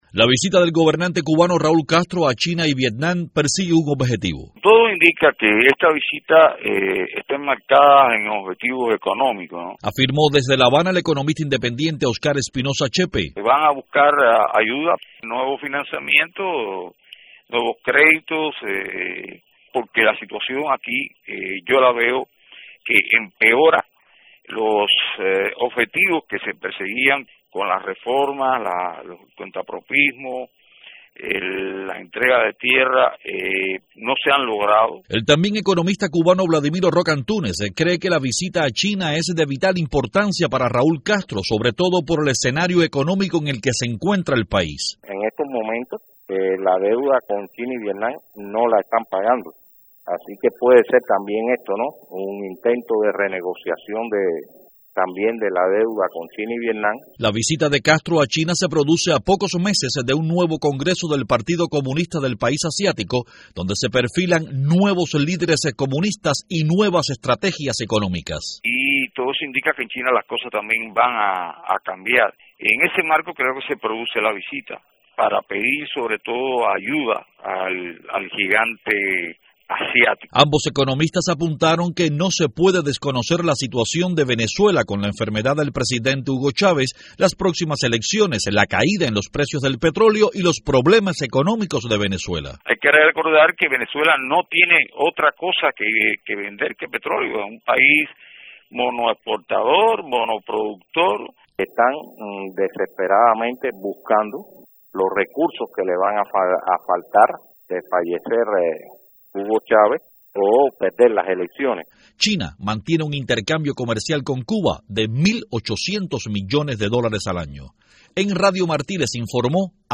Dos economistas conversan